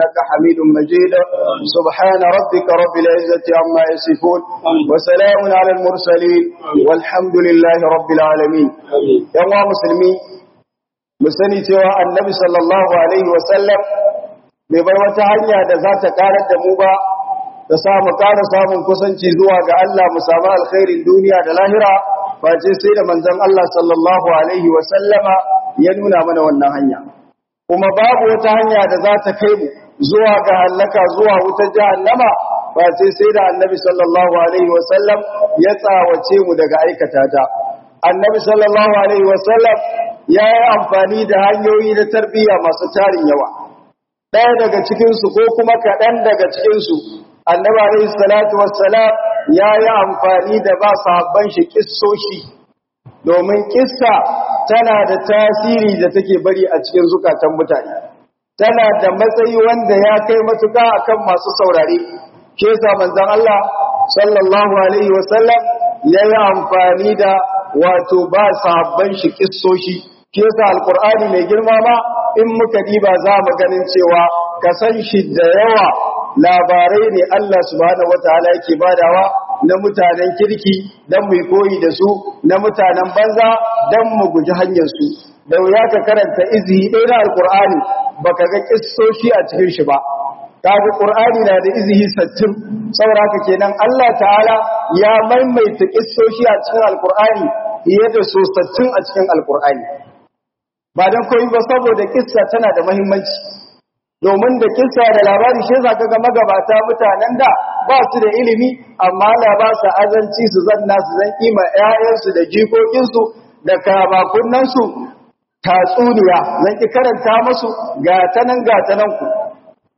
hudubar Juma'a 11 oct._ 2024(3)